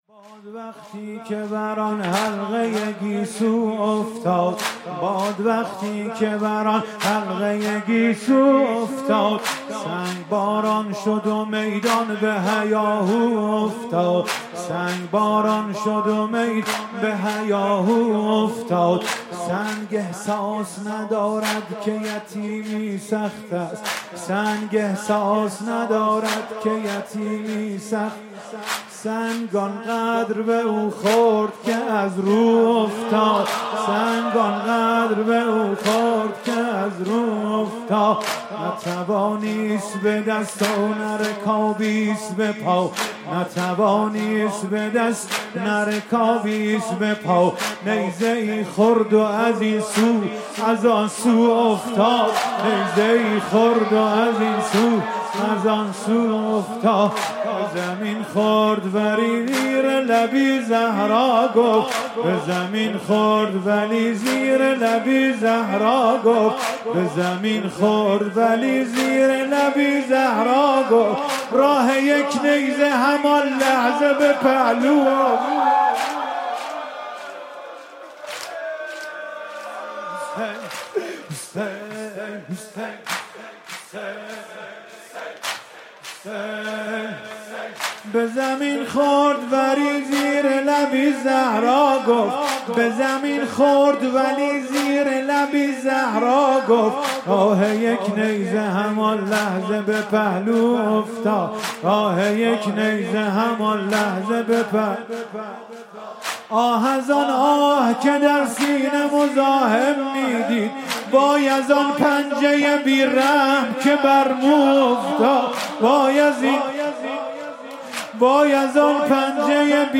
محرم 96